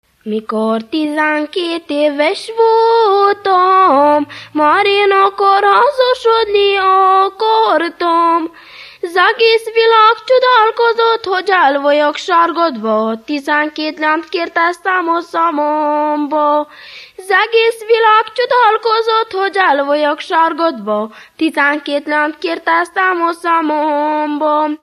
Moldva és Bukovina - Moldva - Külsőrekecsin
Stílus: 8. Újszerű kisambitusú dallamok
Kadencia: 3 (2) V 1